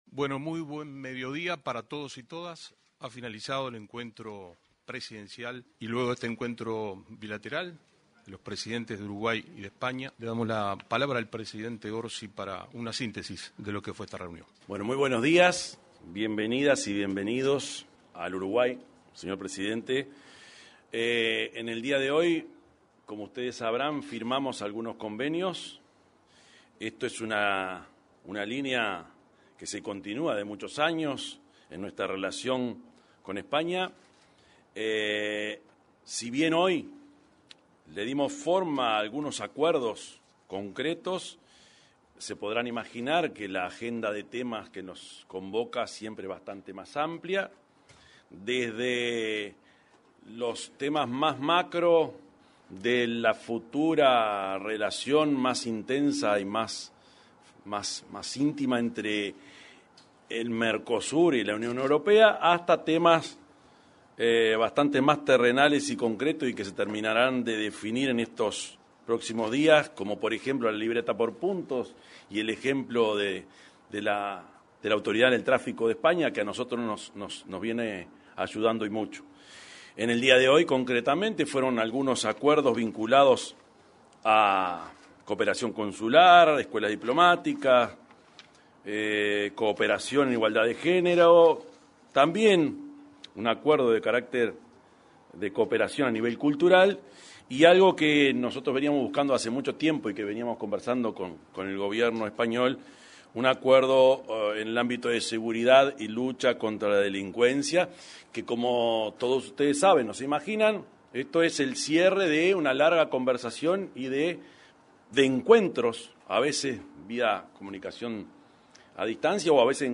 Conferencia de prensa de los presidentes de Uruguay y España
Los mandatarios de Uruguay y España, Yamandú Orsi y Pedro Sánchez, respectivamente, se expresaron en una conferencia de prensa en la Torre Ejecutiva.